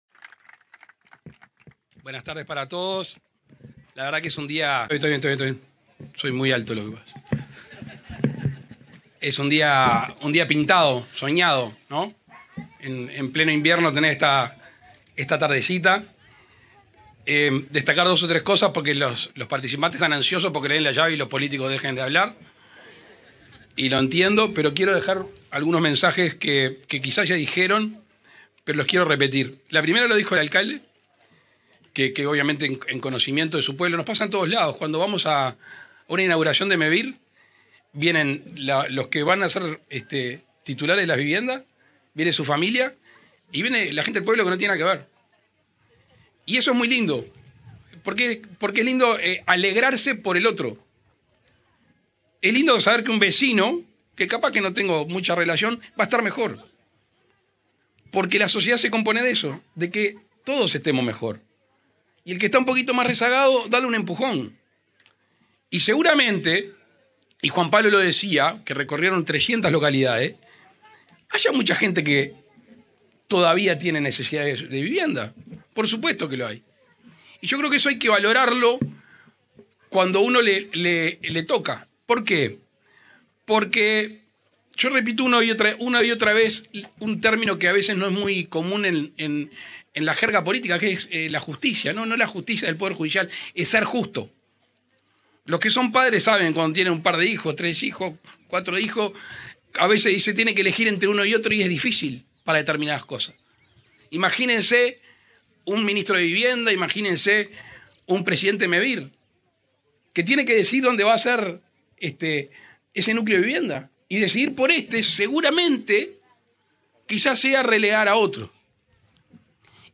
Palabras del presidente de la República Luis Lacalle Pou
En el marco de la entrega de 47 viviendas sustentables de Mevir construidas en madera, en el departamento de Tacuarembó, este 26 de julio, se expresó